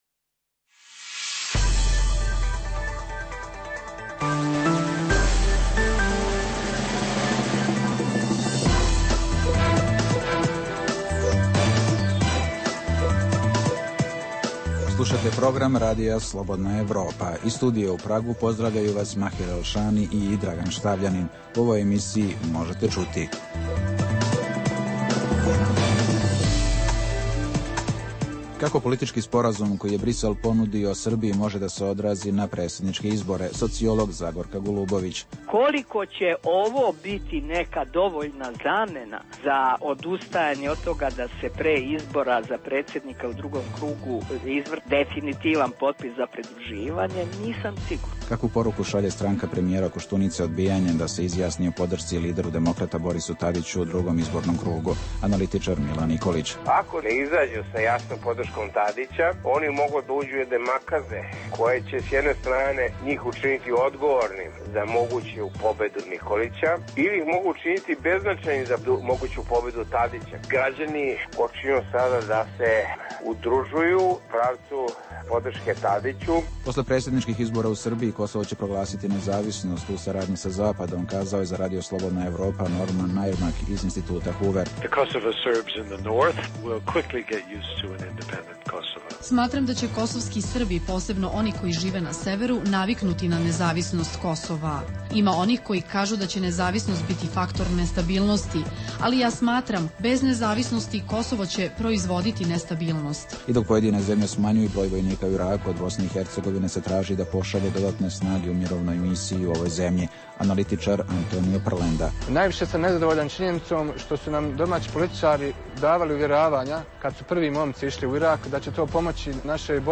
Beležimo: - reakcije na ponudu EU Srbiji o proširenju trgovinske saradnje i liberalizaciji cena, umesto potpisivanja Sporazuma o stabilizaciji i pridruživanju. - Intervju sa Normanom Nejmarkom sa Huverovog instituta o prilikama na Kosovu i Srbiji.